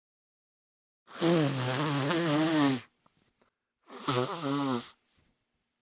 Tiếng Xì mũi, Hỉ mũi nước (giọng phụ nữ)
Thể loại: Tiếng hoạt động con người
Description: Hiệu ứng ghi lại hành động xì mũi có nước – một người phụ nữ đang xì hơi mạnh qua mũi, hỉ mũi liên tục, xì nước mũi ra ngoài, với âm thanh rõ ràng và chân thực. Tiếng xì mũi còn được biết đến qua các biểu hiện như khịt mũi, khụt khịt, sụt sịt, hay tiếng thở mạnh qua mũi khi bị nghẹt – tất cả được tái hiện một cách sinh động trong hiệu ứng này.
tieng-xi-mui-hi-mui-nuoc-giong-phu-nu-www_tiengdong_com.mp3